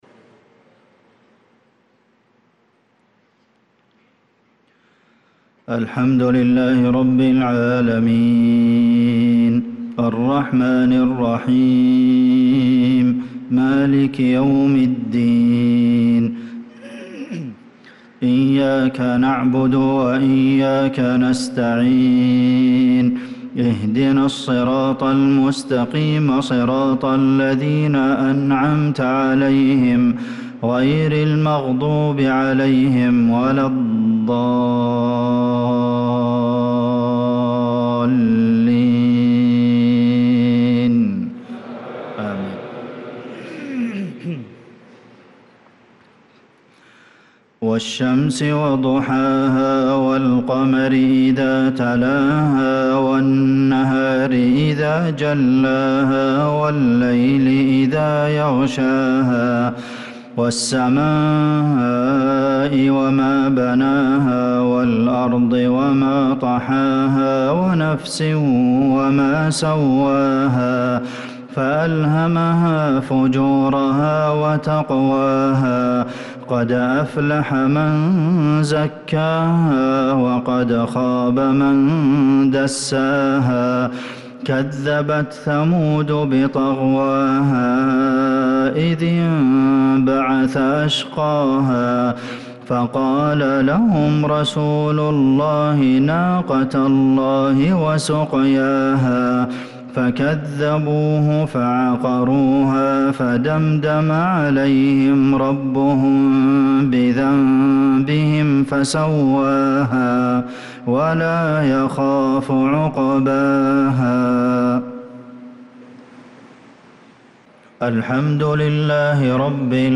صلاة المغرب